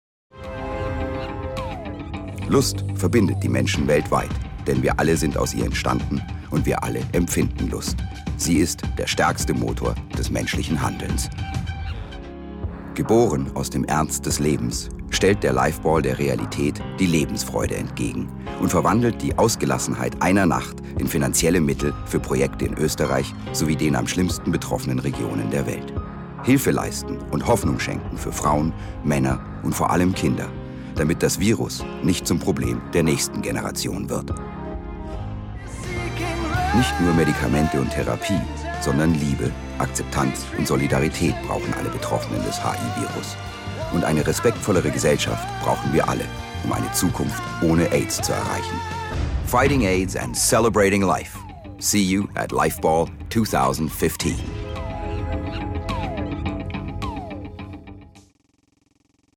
dunkel, sonor, souverän
Mittel plus (35-65)
Bayrisch
Lip-Sync (Synchron)